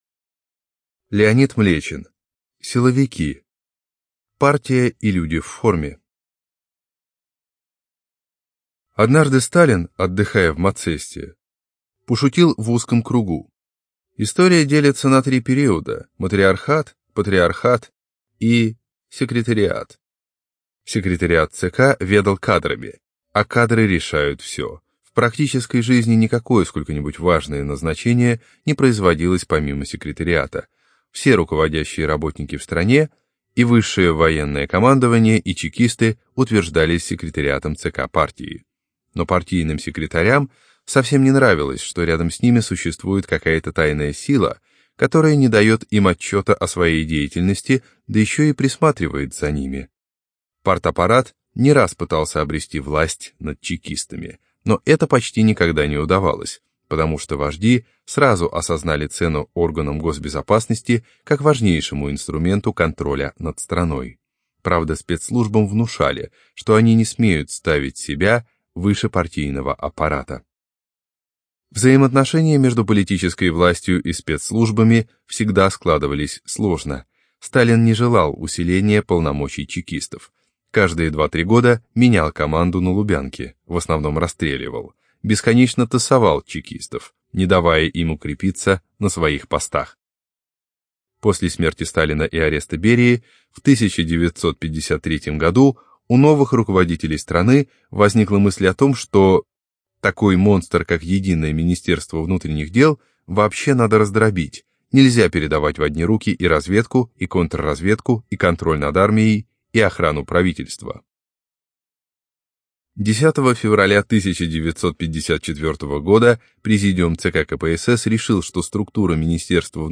ЖанрПублицистика, Биографии и мемуары
Студия звукозаписиАрдис